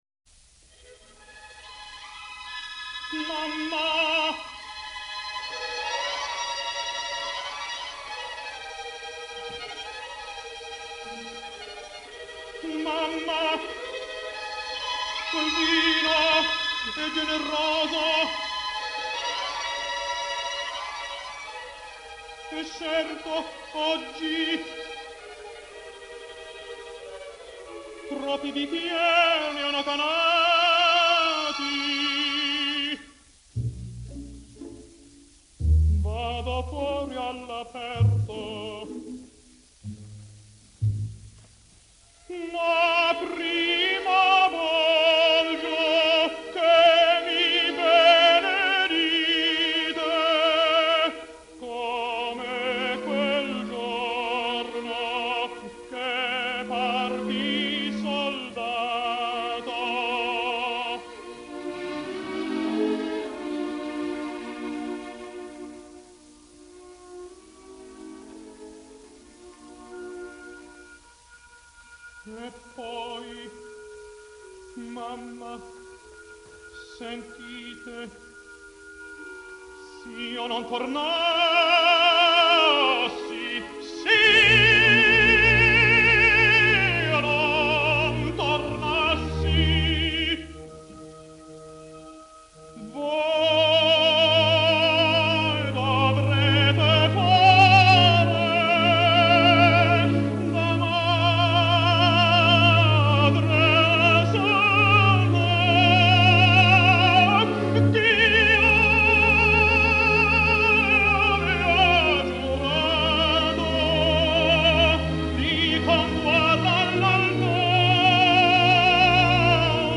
A voice with a tear in it in Addio alla madre …He sang in German, Italian, French, Swedish and English.